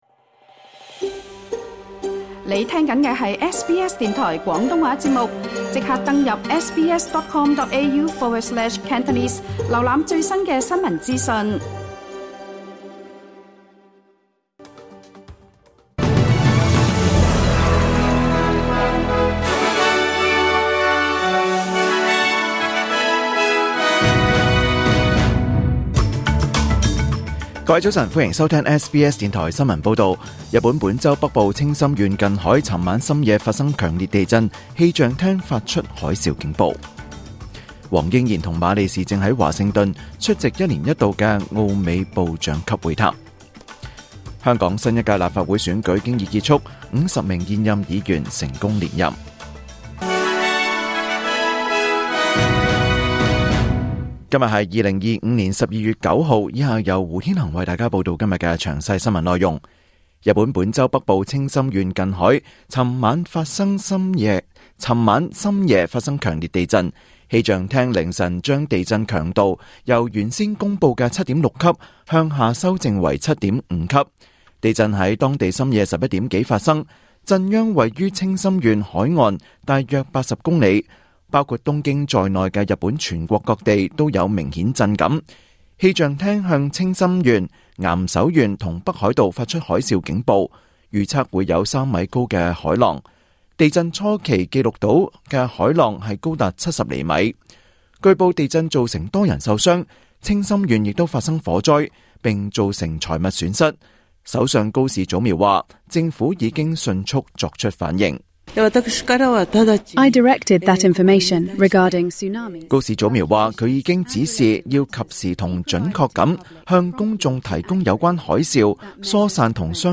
2025年12月9日SBS廣東話節目九點半新聞報道。